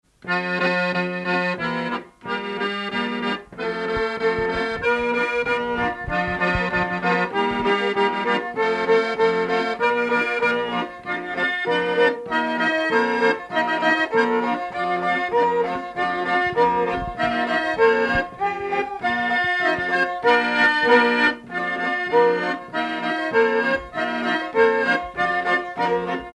danse : polka
circonstance : bal, dancerie
Pièce musicale inédite